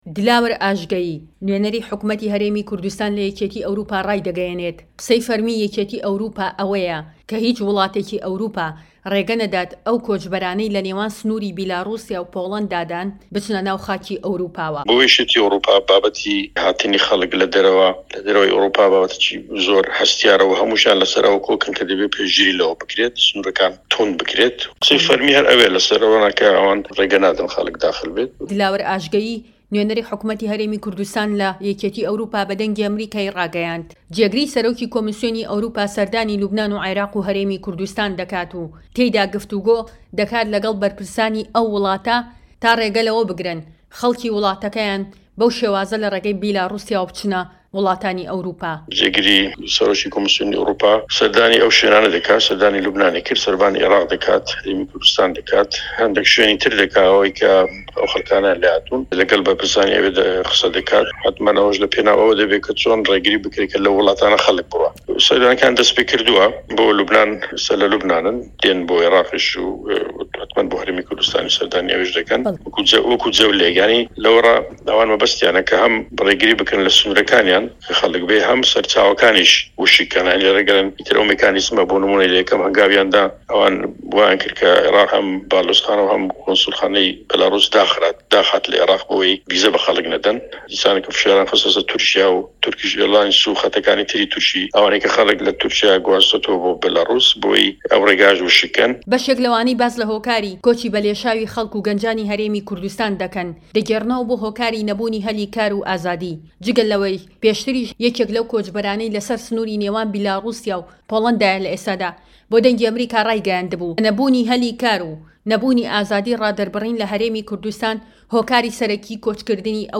دلاوەر ئاژگەیی نوێنەری حکومەتی هەرێمی کوردستان لە یەکێتی ئەوروپا